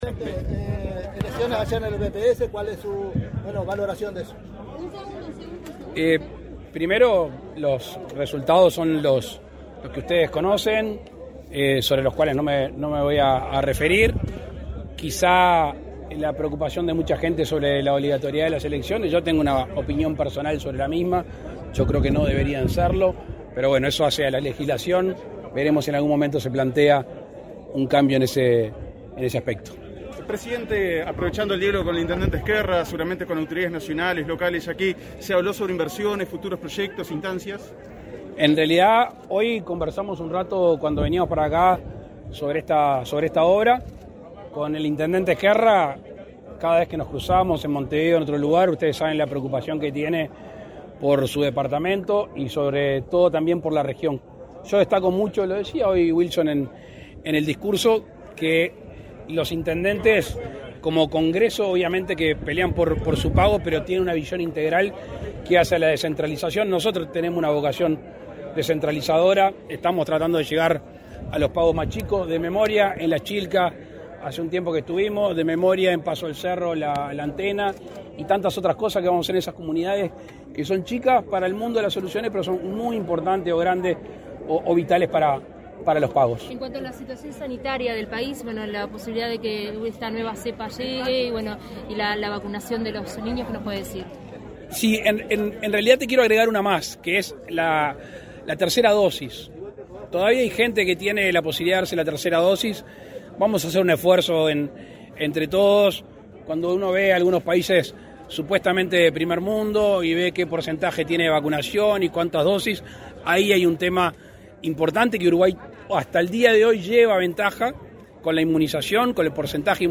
Declaraciones de prensa del presidente de la República, Luis Lacalle Pou
Declaraciones de prensa del presidente de la República, Luis Lacalle Pou 29/11/2021 Compartir Facebook X Copiar enlace WhatsApp LinkedIn Tras participar en la inauguración de la Residencia Universitaria en Tacuarembó, este 29 de noviembre, el presidente Luis Lacalle Pou efectuó declaraciones a la prensa.